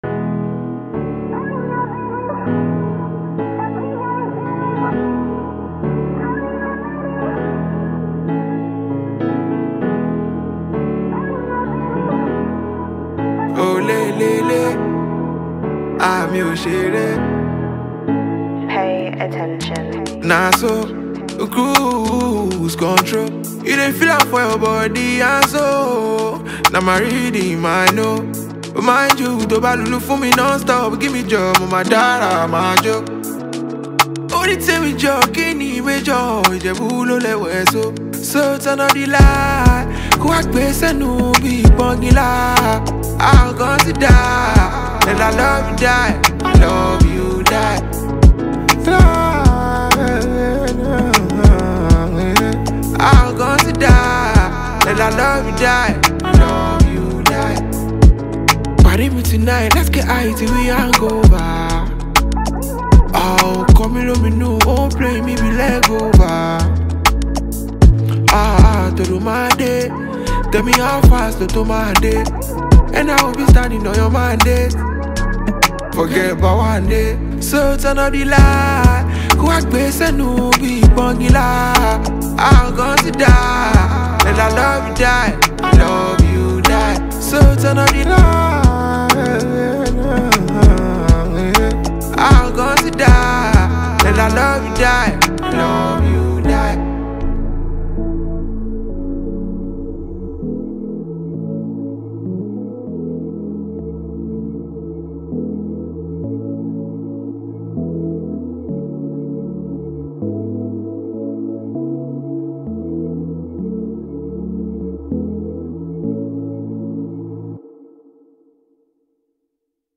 rave-worthy and urban grooving music